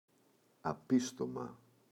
απίστομα [a’pistoma]